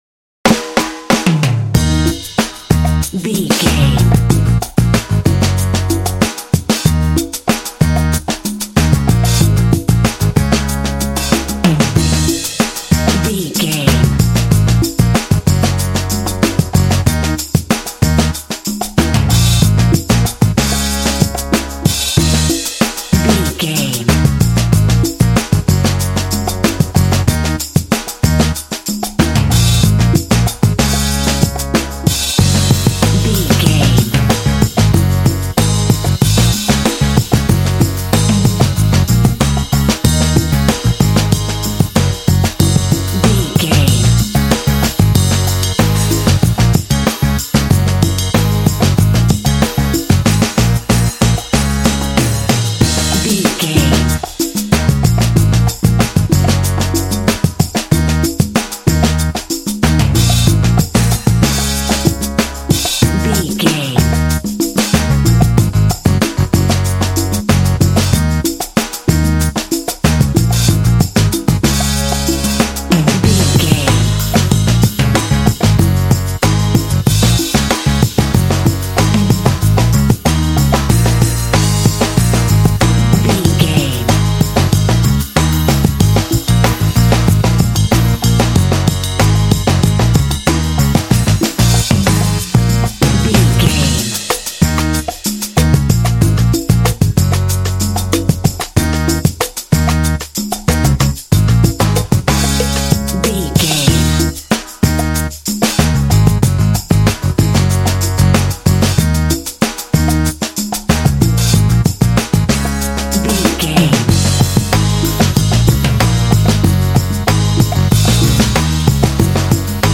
This cool and funky track is great for action games.
Aeolian/Minor
E♭
groovy
funky
driving
saxophone
drums
percussion
bass guitar